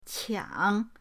qiang3.mp3